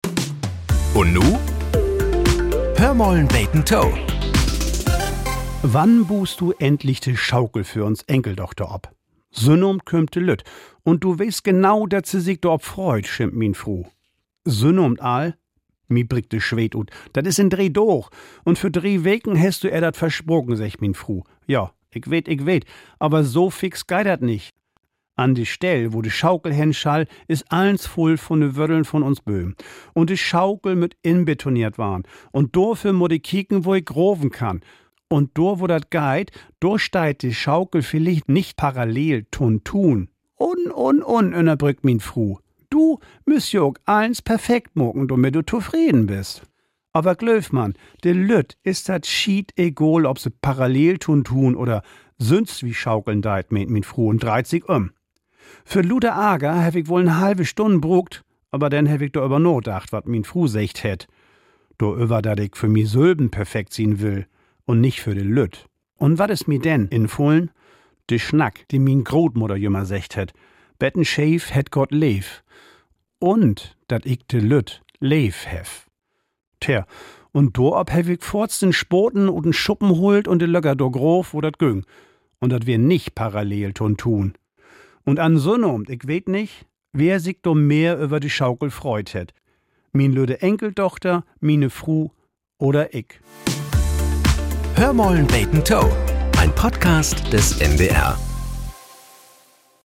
Nachrichten - 27.04.2025